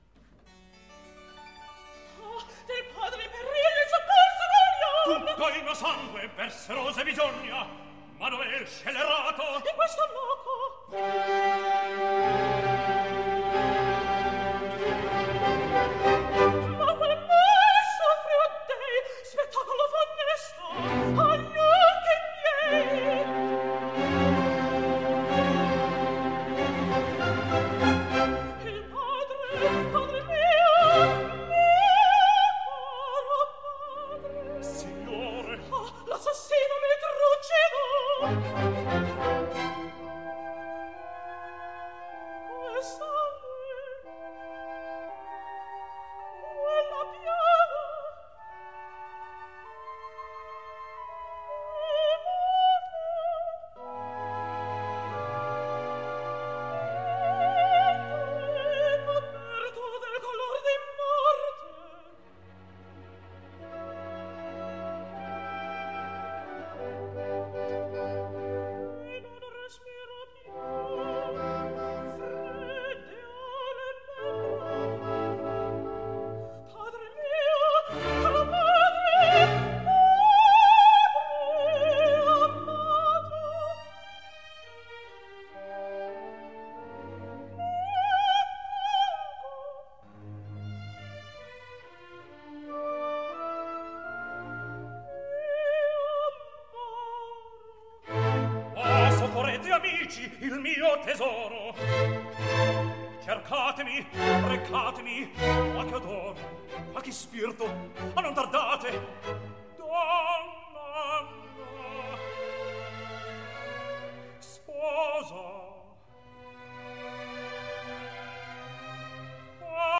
Opera buffa
1706 - 1850 (Baroque, Classical)